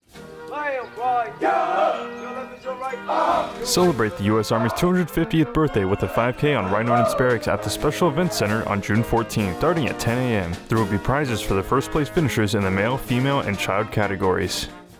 Radio Spot - Army 250 5K